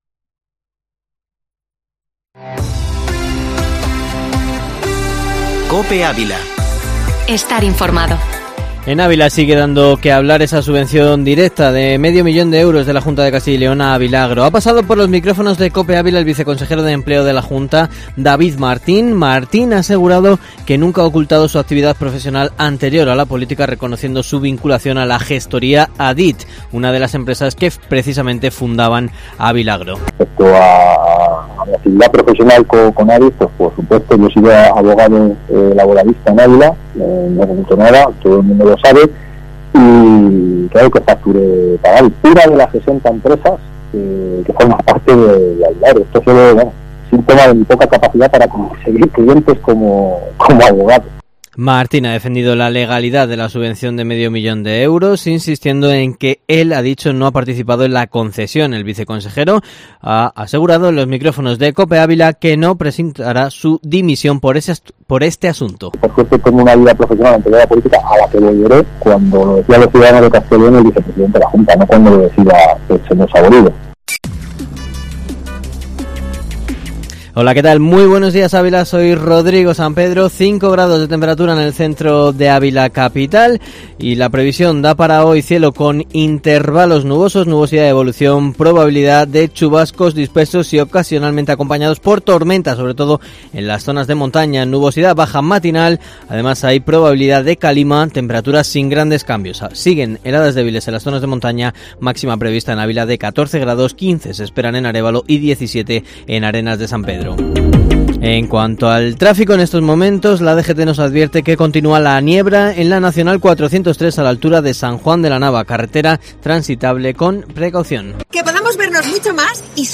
Informativo matinal Herrera en COPE Ávila 03/03/2021